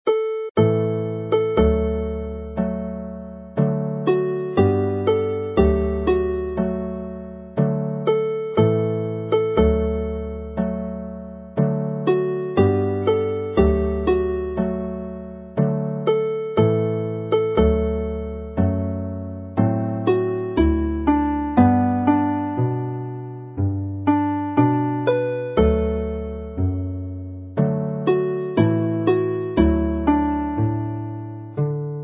Alawon Cymreig - Set Ar Lan y Môr set - Welsh folk tunes